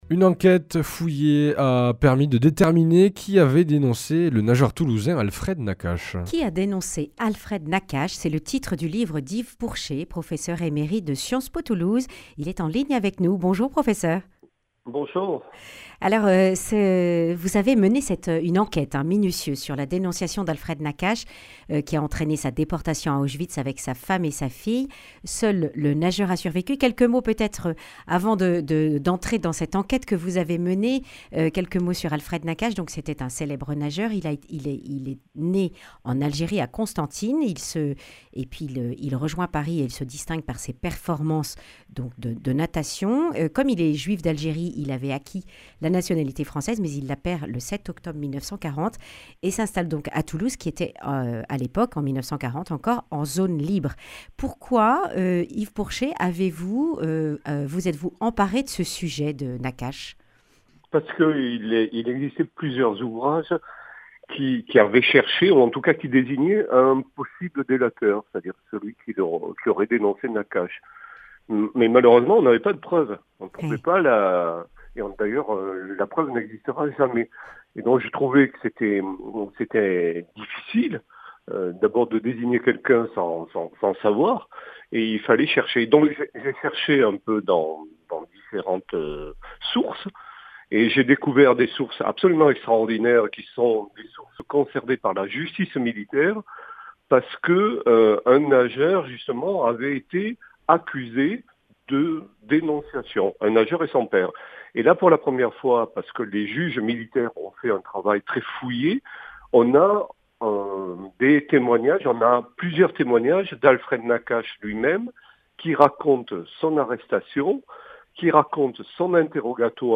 Accueil \ Emissions \ Information \ Régionale \ Le grand entretien \ Qui a dénoncé Nakache ?